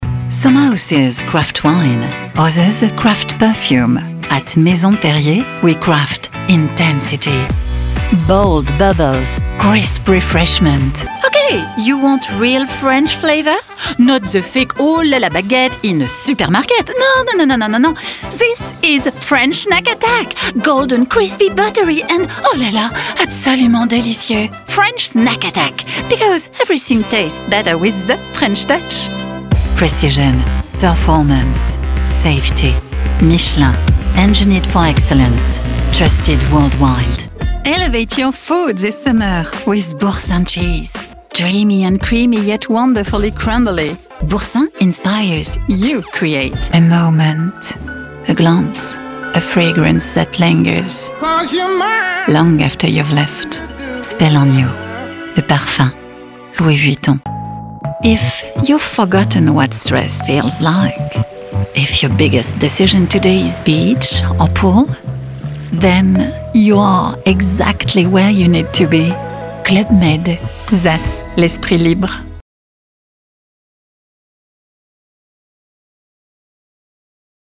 Female
Assured, Authoritative, Bright, Bubbly, Character, Cheeky, Children, Confident, Cool, Corporate, Deep, Engaging, Friendly, Gravitas, Natural, Posh, Reassuring, Sarcastic, Smooth, Soft, Streetwise, Wacky, Warm, Witty, Versatile, Young
Microphone: Neumann U87 + TLM103
Audio equipment: Apollo twin + RME fire Face ; Manley Vox Box, Booth acoustically treated